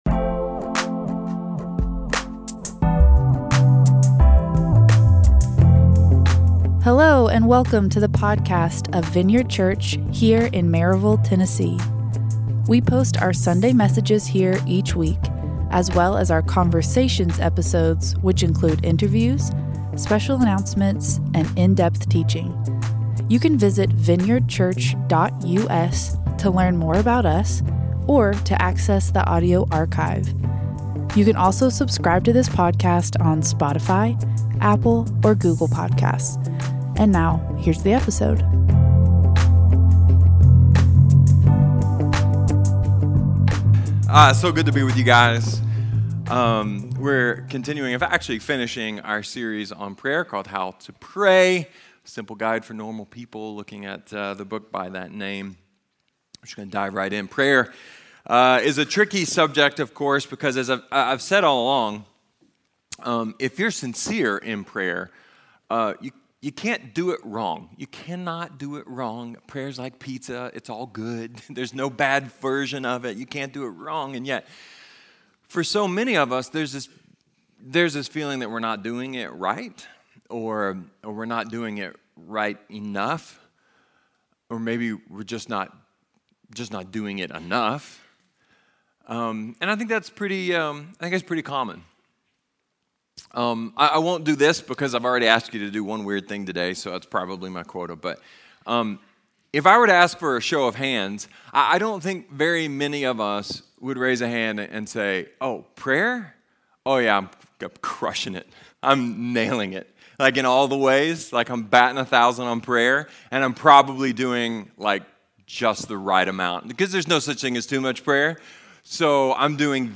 A message from the series "How to Pray."